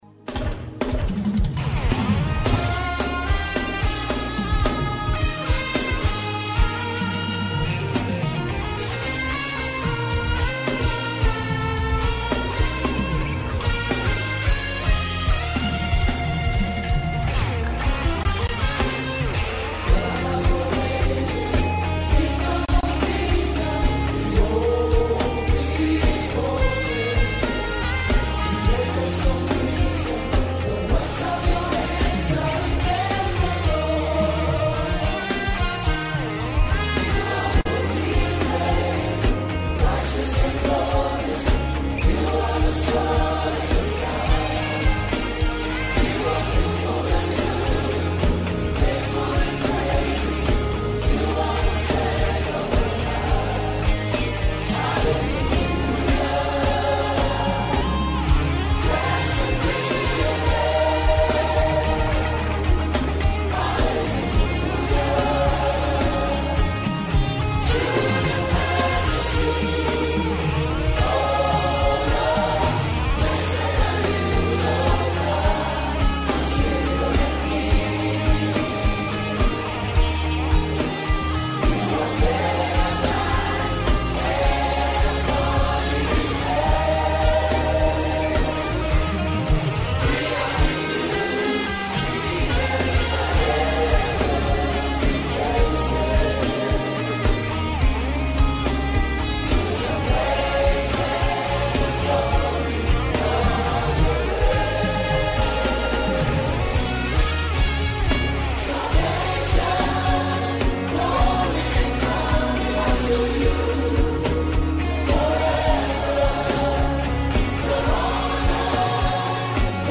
I felt so calm when I was listening to it.